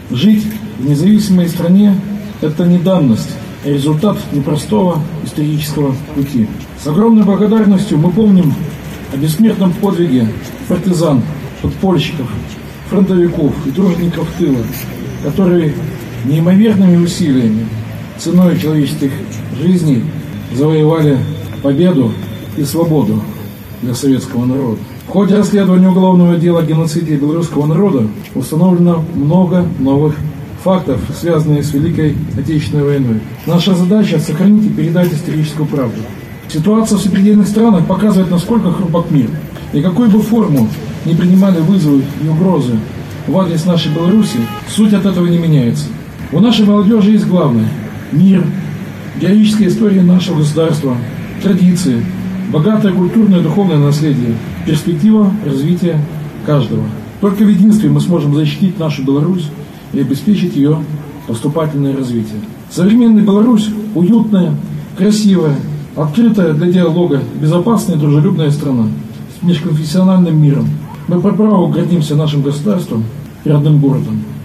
У памятника воинам и партизанам, освобождавшим город от немецко-фашистских захватчиков, состоялся митинг. С главным государственным праздником горожан, ветеранов и гостей нашего города поздравил председатель горисполкома Михаил Баценко. С огромной благодарностью мы помним бессмертные подвиги партизан, подпольщиков, фронтовиков, тружеников тыла, которые неимоверными усилиями, ценой человеческих жизней завоевали победу и свободу для советского народа, сказал Михаил Баценко.